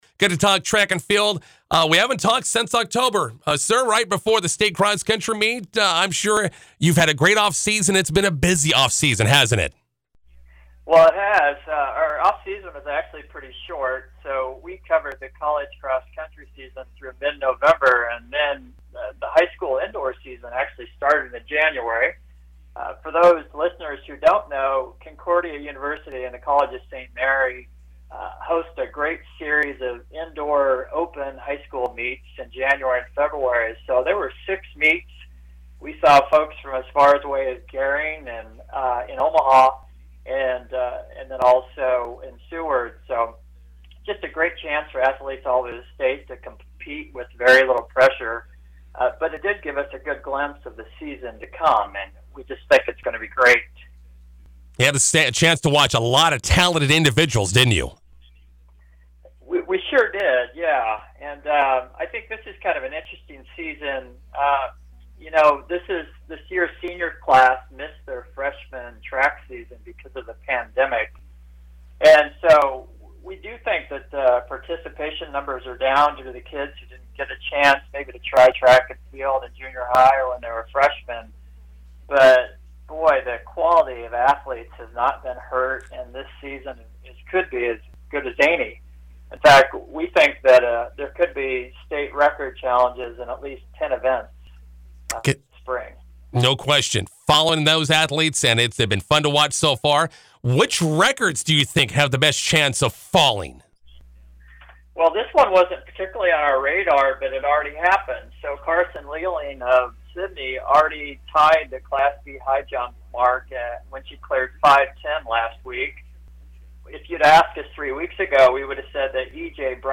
McCook radio interview
Here is our interview from earlier today: